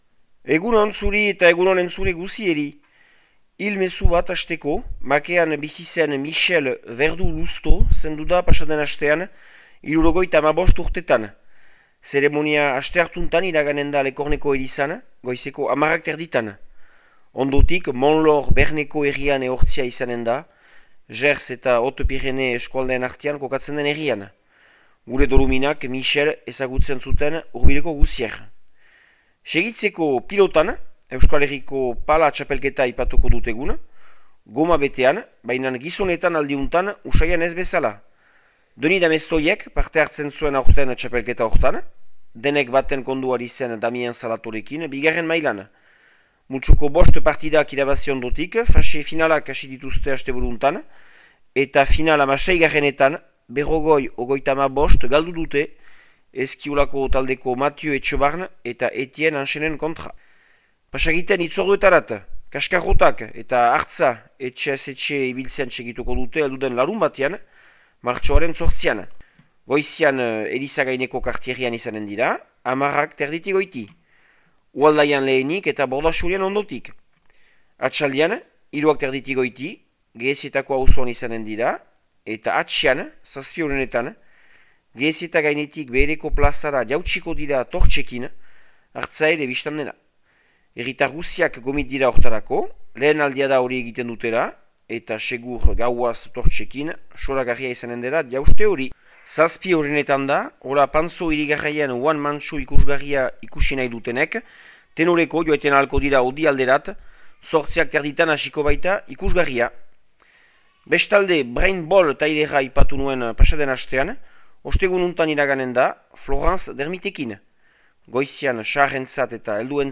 Martxoaren 3ko Makea eta Lekorneko berriak